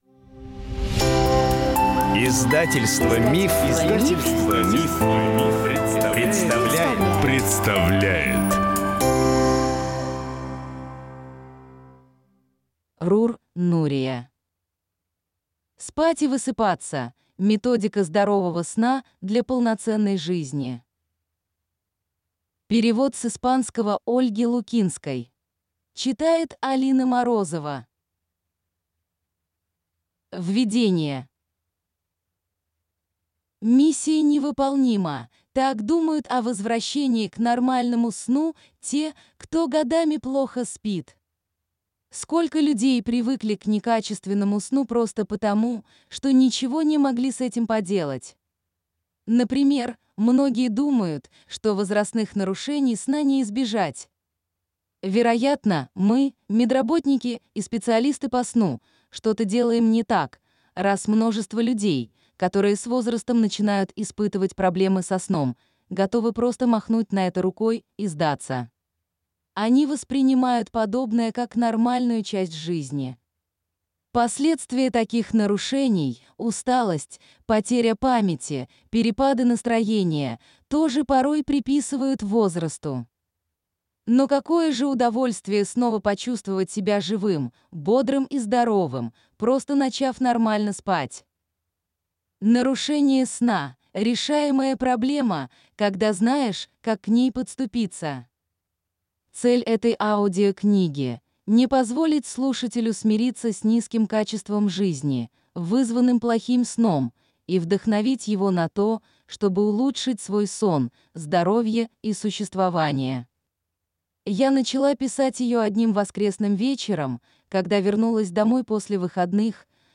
Аудиокнига Спать и высыпаться. Методика здорового сна для полноценной жизни | Библиотека аудиокниг